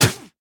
1.21.5 / assets / minecraft / sounds / mob / llama / spit2.ogg
spit2.ogg